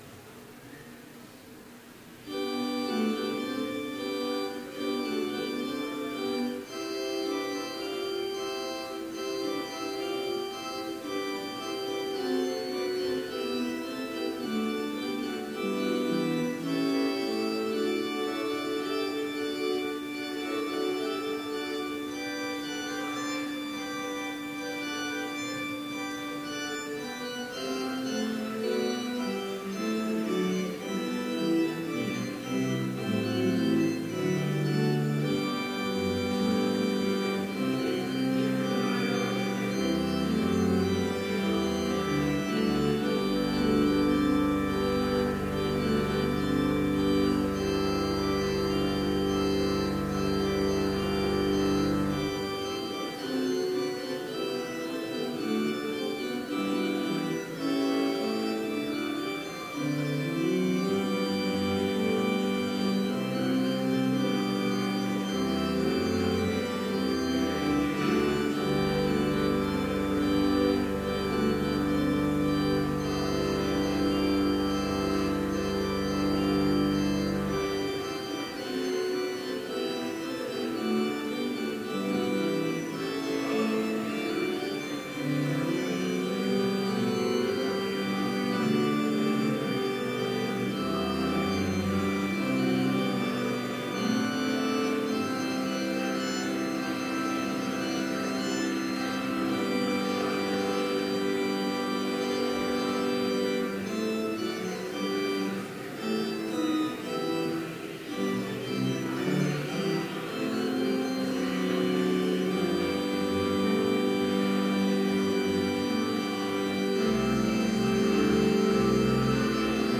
Complete service audio for Chapel - January 9, 2017